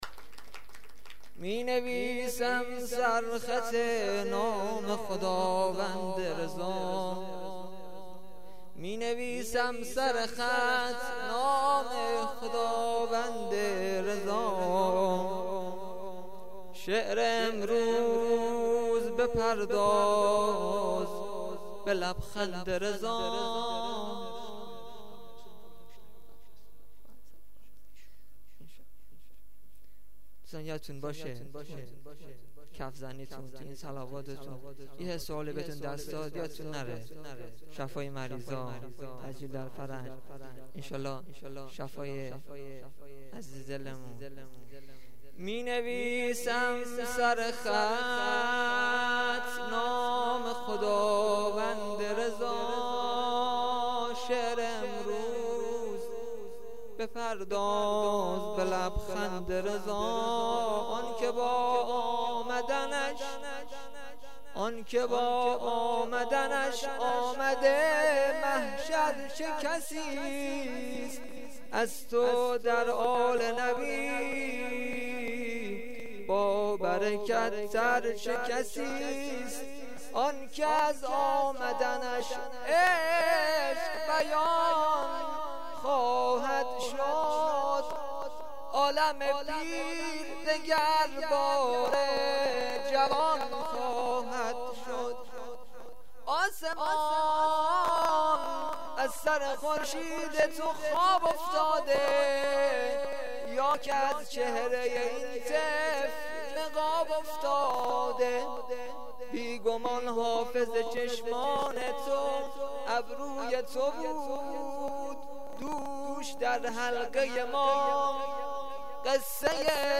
مدح وسرود میلاد امام جواد.mp3
مدح-وسرود-میلاد-امام-جواد.mp3